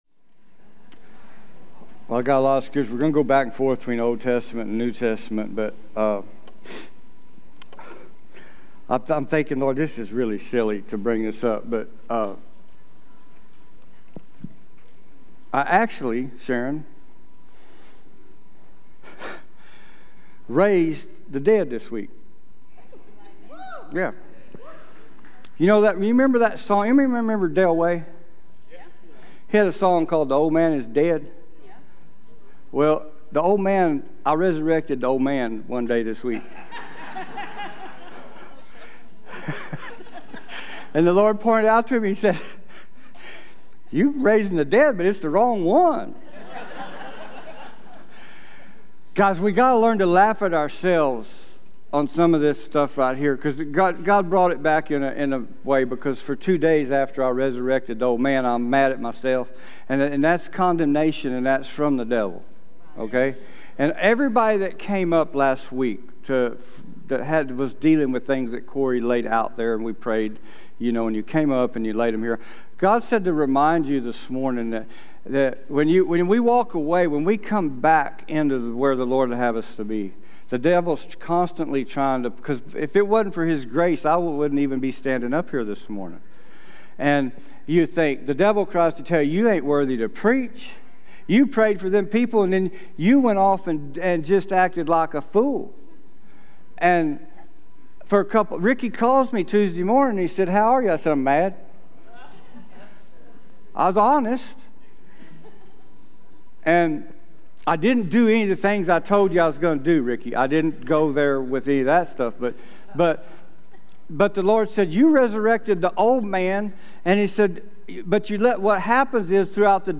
Sermons Archive ⋆ Page 14 of 45 ⋆ Williamson County Cowboy Church - Liberty Hill, TX